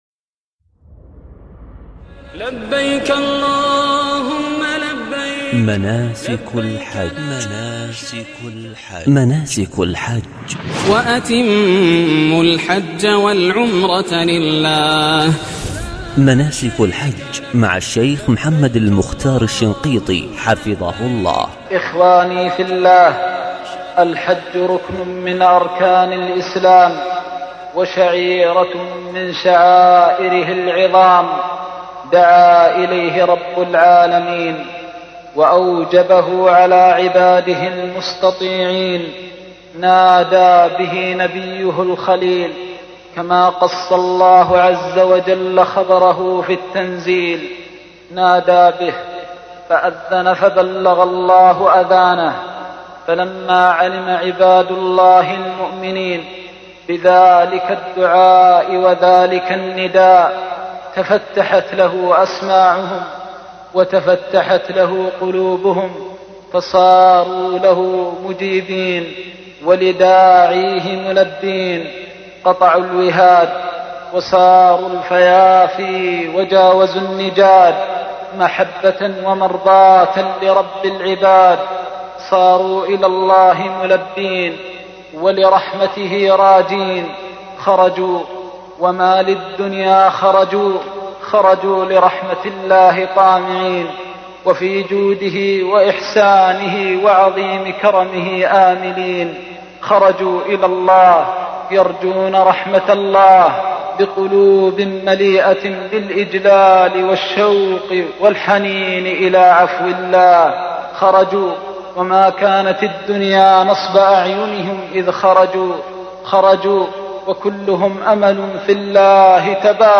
محاضرة مبكية بعنوان (دمــعـــة فــي الــحــج) لمعالي الشيخ محمد المختار الشنقيطي